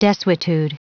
Prononciation du mot desuetude en anglais (fichier audio)
Prononciation du mot : desuetude